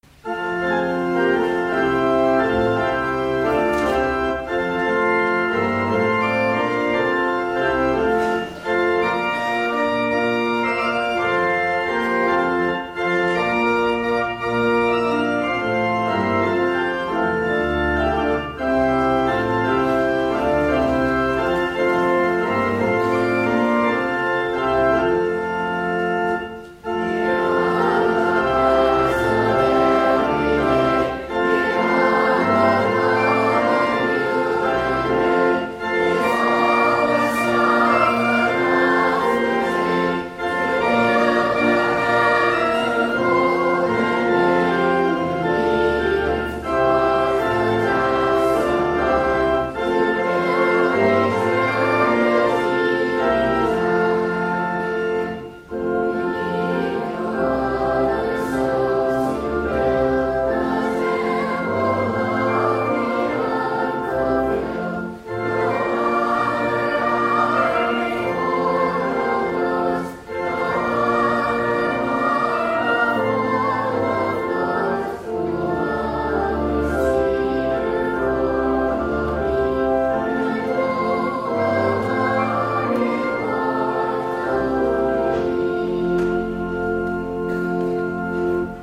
4-hymn-here-on-the-paths-of-everyday-no-312.mp3